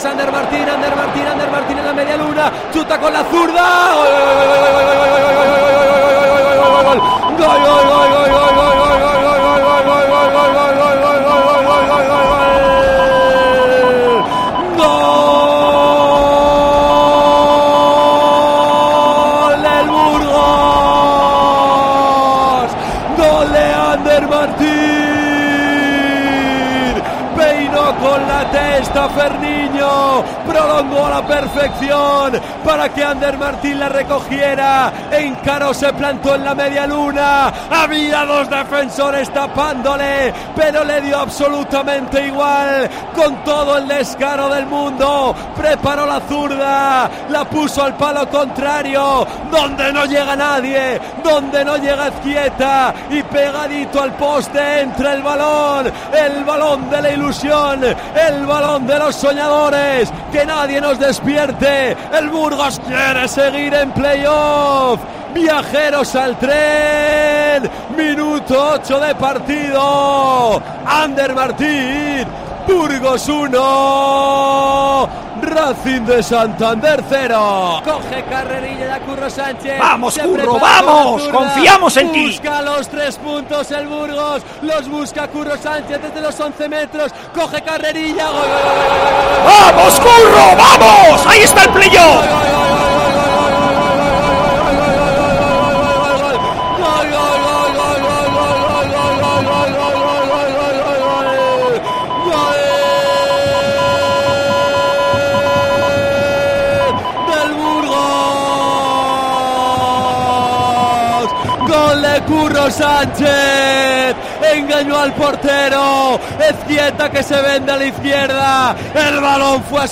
Así narramos en COPE los dos goles de Burgos CF al Racing de Santander